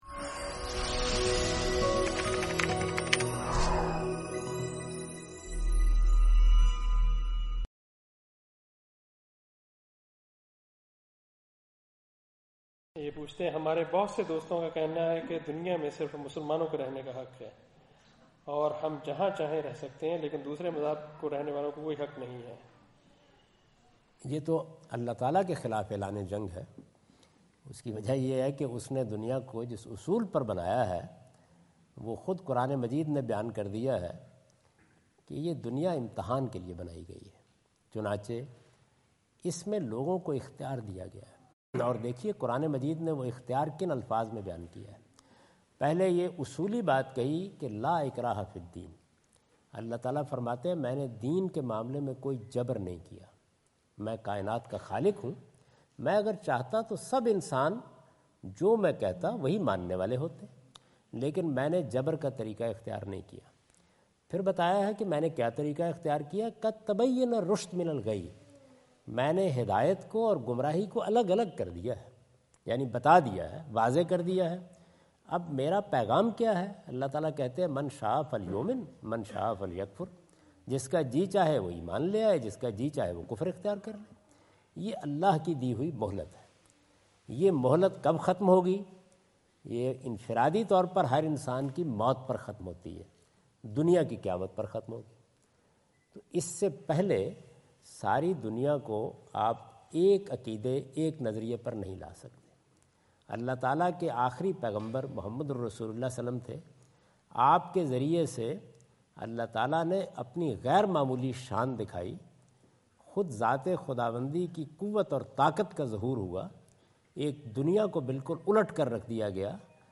Category: English Subtitled / Questions_Answers /
Javed Ahmad Ghamidi answer the question about "Does The World Belong to Muslims only?" during his visit in Canberra Australia on 03rd October 2015.